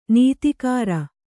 ♪ nītikāra